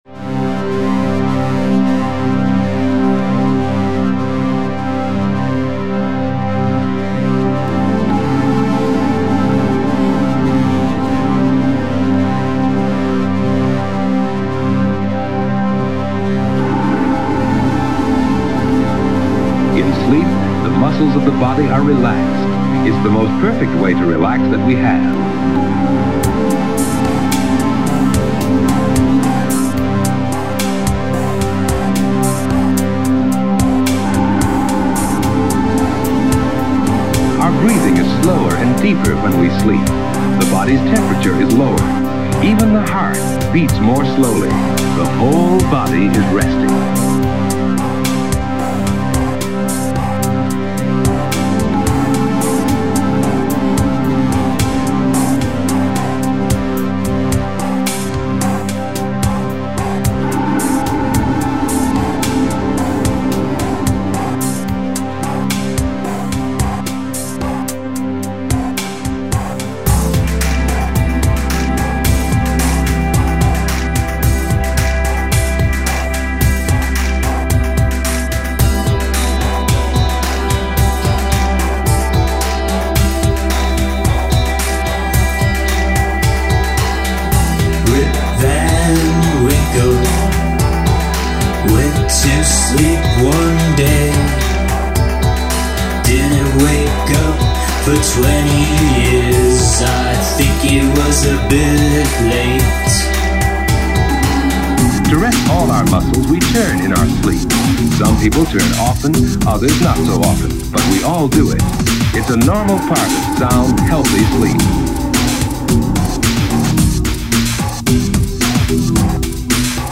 No rhyming